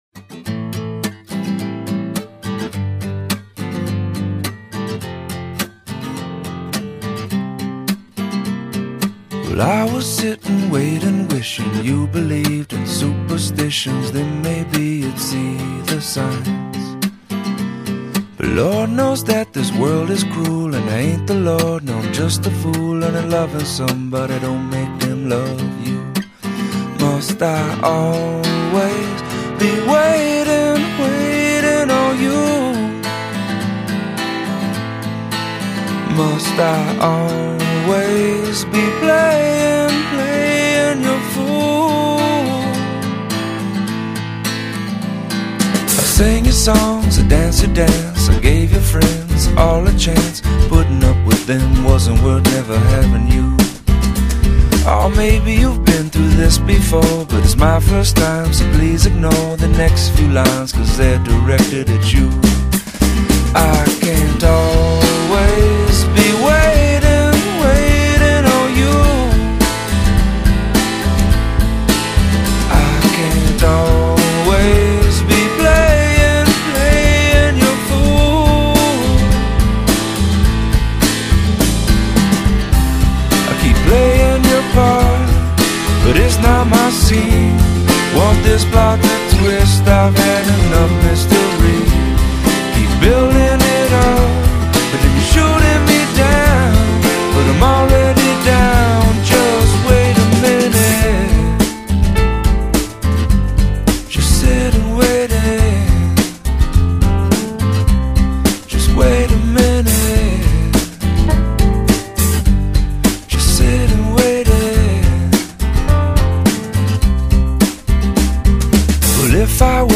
簡單動人的音樂並不用複雜的解釋